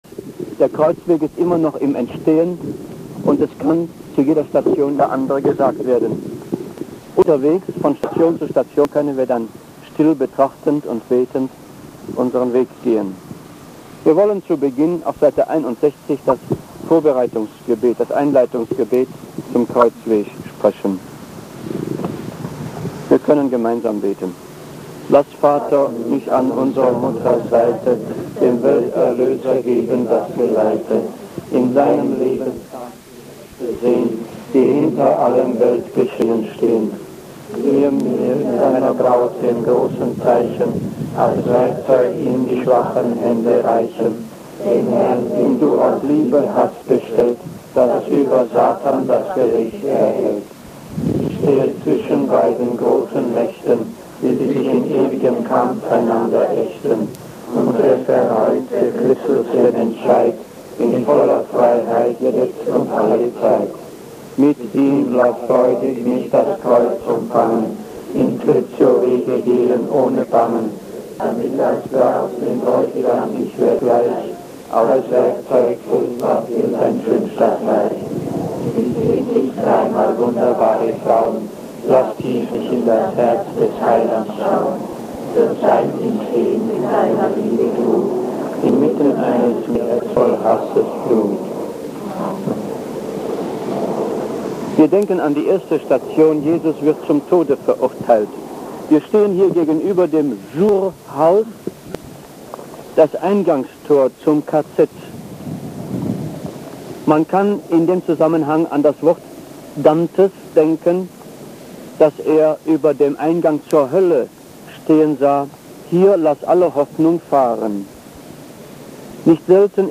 Er hat h�ufig f�r Gruppen der Sch�nstattbewegung F�hrungen auf dem Gel�nde des KZ Dachau gehalten. Davon stehen uns zwei Tonbandaufnahmen zur Verf�gung, die als Audio (mp3)�f�rs Internet aufgearbeitet wurden.